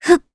Valance-Vox_Jump_jp_c.wav